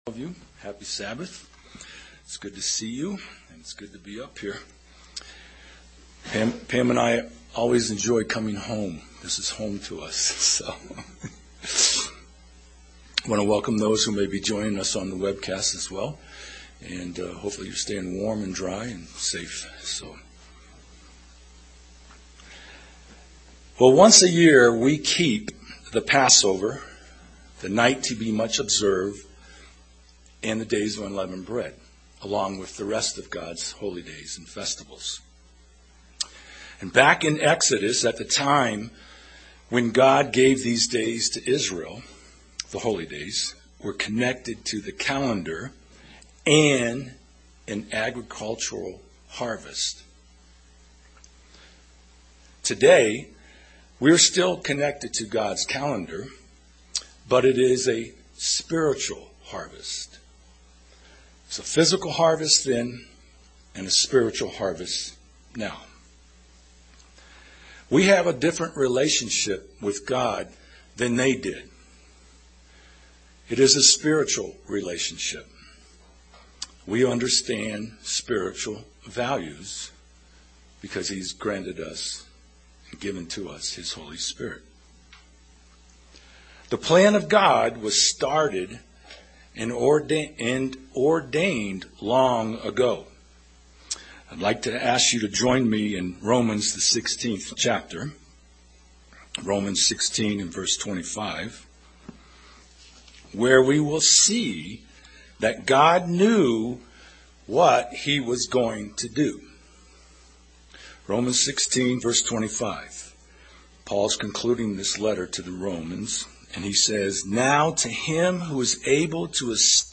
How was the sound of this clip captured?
Given in Denver, CO Loveland, CO